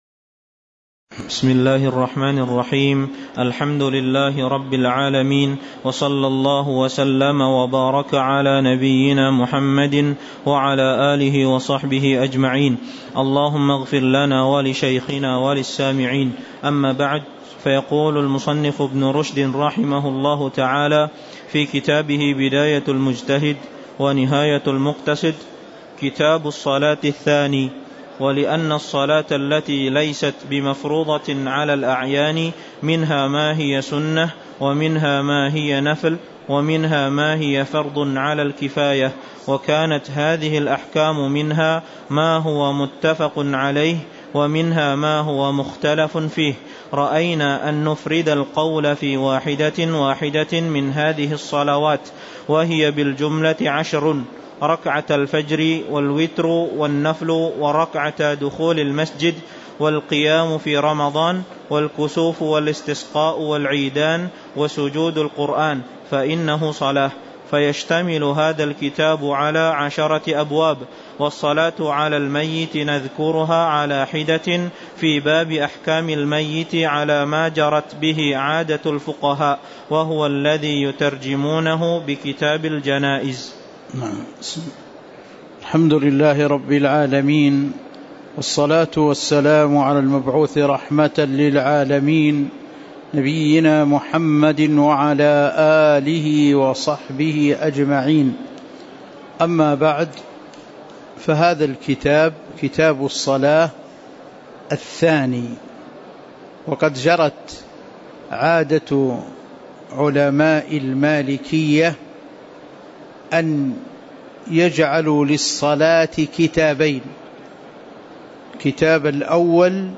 تاريخ النشر ١٠ جمادى الأولى ١٤٤٤ هـ المكان: المسجد النبوي الشيخ